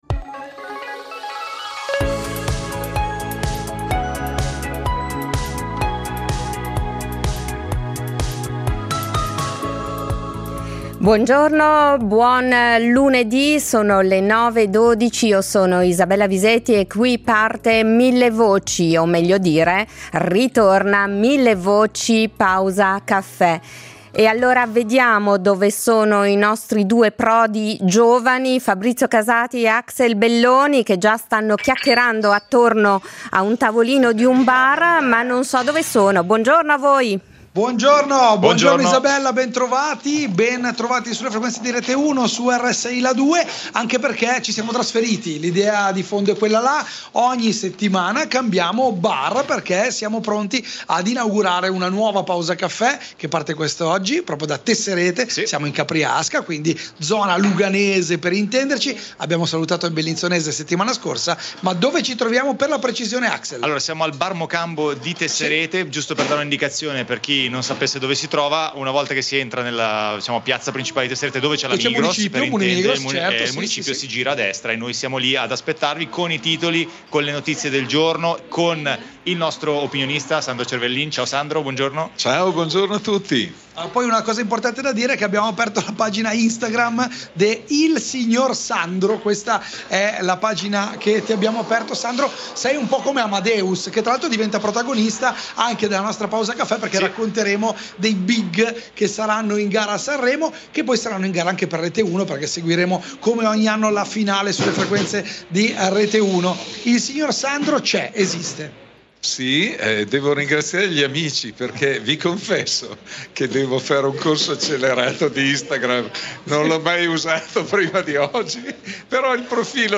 Ogni giorno, dal lunedi al venerdi, vi porteremo in un bar della regione per conoscere il territorio, viverlo da vicino, scoprire storie e commentare notizie curiose in compagnia.